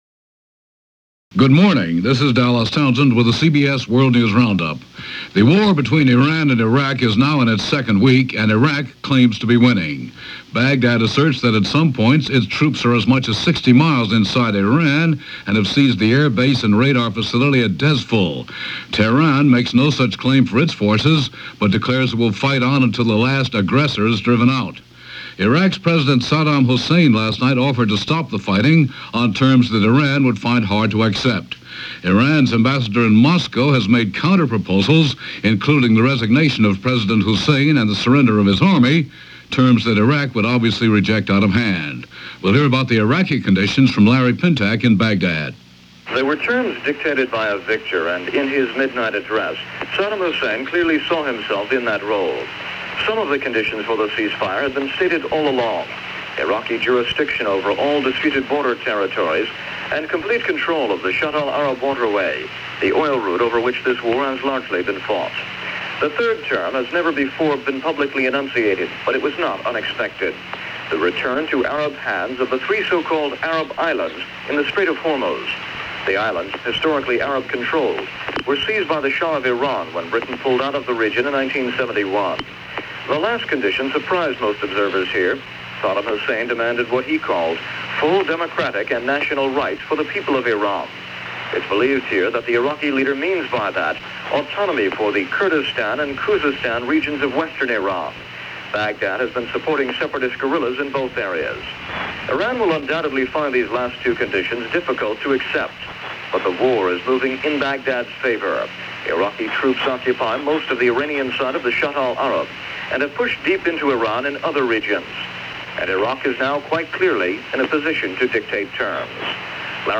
CBS World News Roundup – September 29, 1080 – Gordon Skene Sound Collection